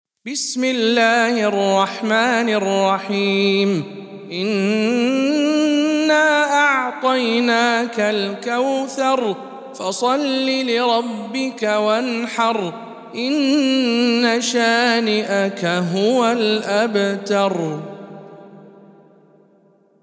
سورة الكوثر - رواية رويس عن يعقوب